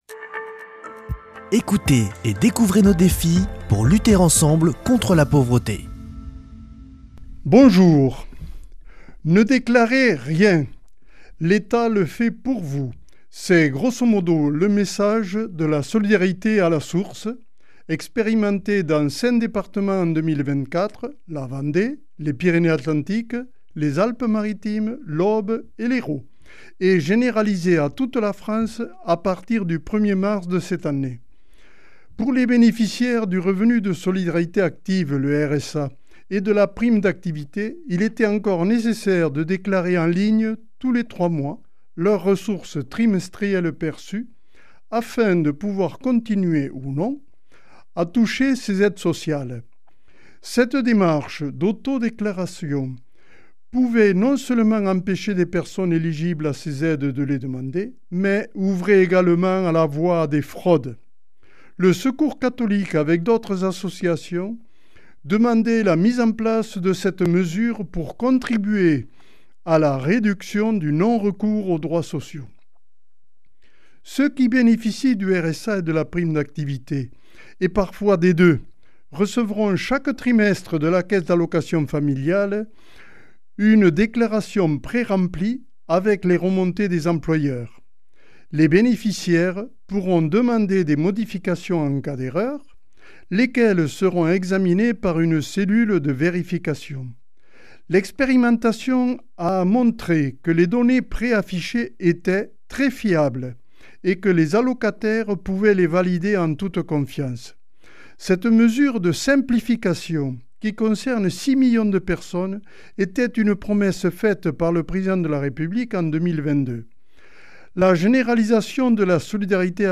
lundi 5 mai 2025 Chronique du Secours Catholique Durée 3 min